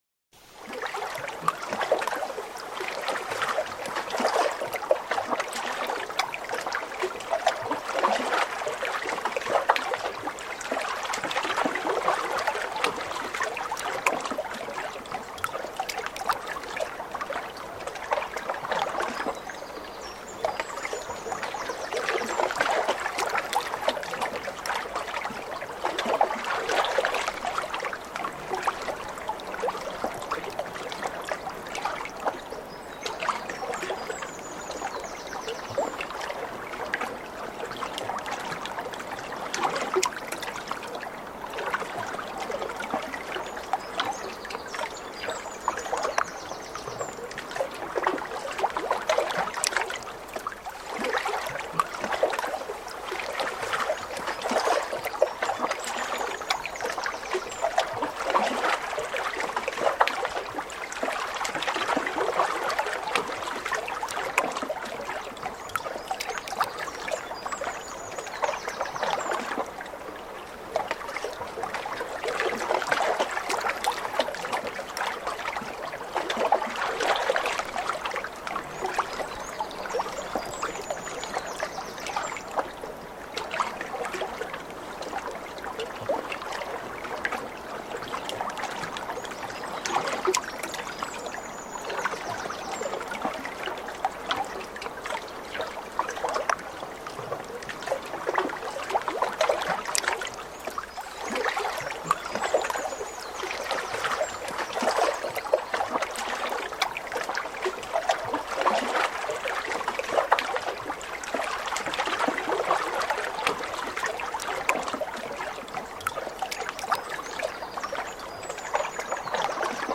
PERFEKTE HARMONIE: Waldstimmen-Perfektion mit Wasser + Vögeln